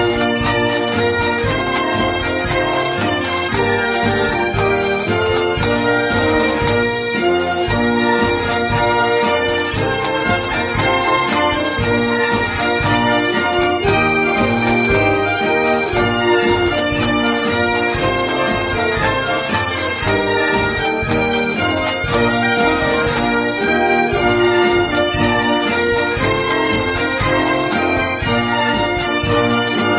New Year's Time Square 2009 Sound Effects Free Download